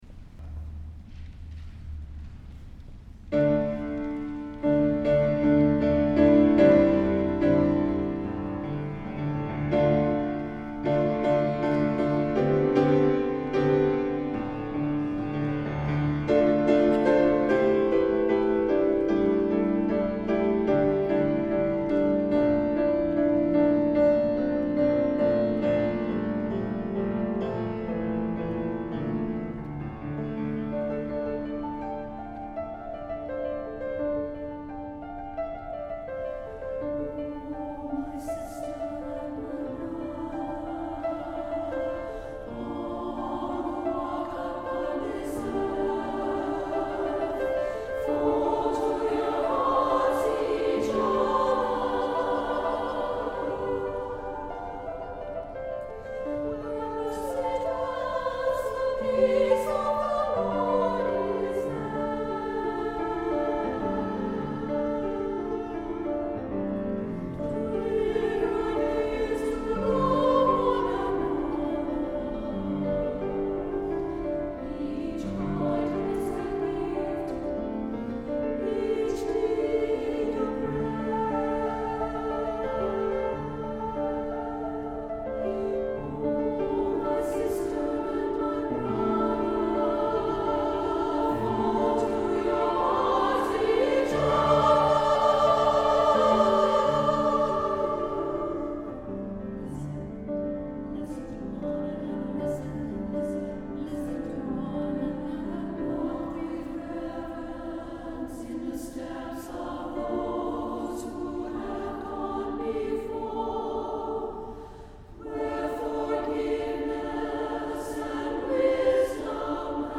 SSA version